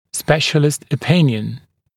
[‘speʃəlɪst ə’pɪnjən][‘спэшэлист э’пинйэн]мнение специалиста